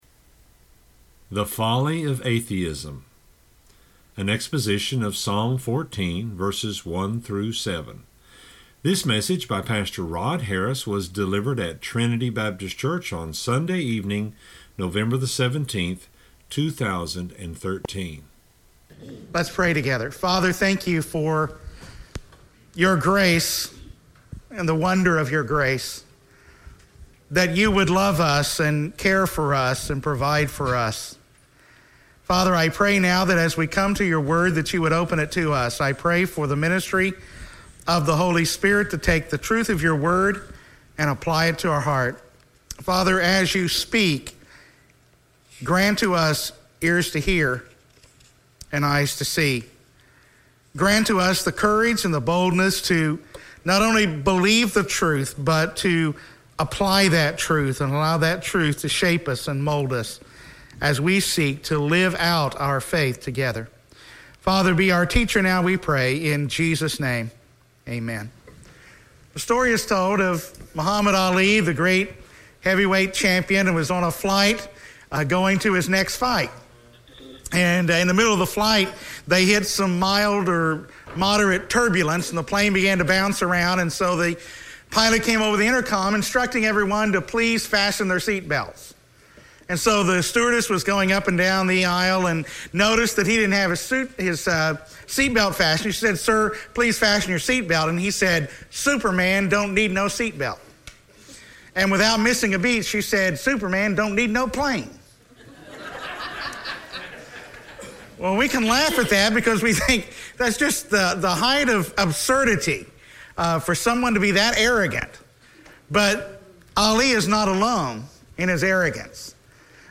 at Trinity Baptist Church on Sunday evening, November 17, 2013.